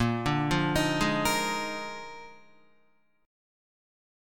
A#11 chord